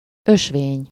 Ääntäminen
Synonyymit weggetje padde wegel tra Ääntäminen Tuntematon aksentti: IPA: /pɑt/ Haettu sana löytyi näillä lähdekielillä: hollanti Käännös Ääninäyte 1. út 2. ösvény Suku: n .